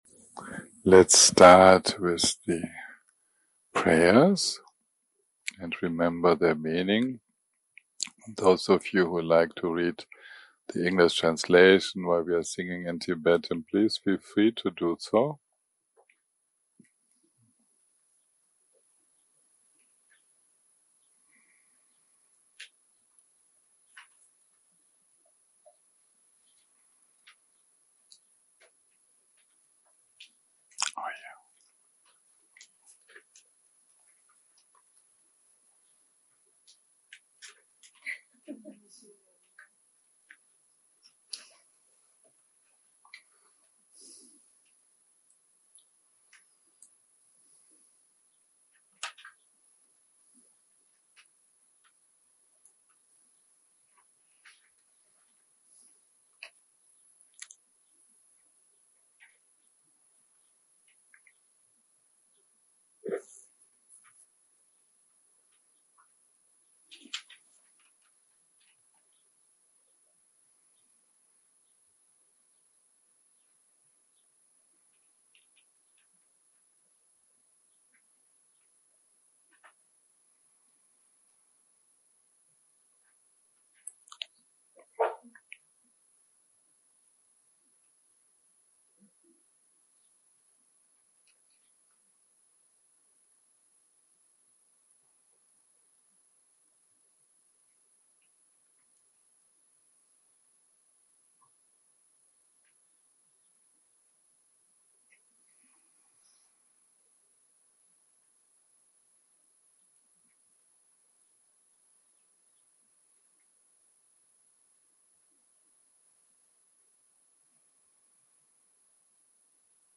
day 6 - recording 19 - Morning - Guided Meditation + Discussion + Guidance - The Nature of the Mind
Dharma type: Guided meditation